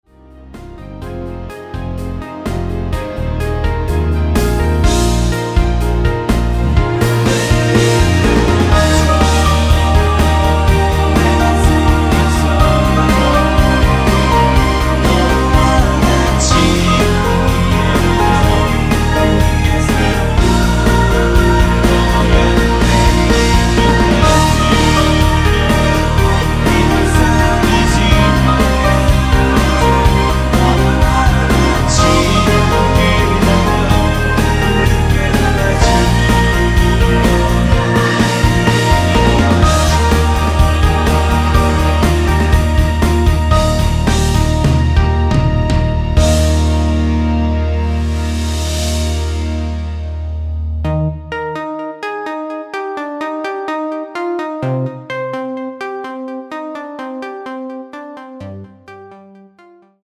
(-1)내린 끝부분 코러스 포함된 MR 입니다.(미리듣기 참조)
◈ 곡명 옆 (-1)은 반음 내림, (+1)은 반음 올림 입니다.
앞부분30초, 뒷부분30초씩 편집해서 올려 드리고 있습니다.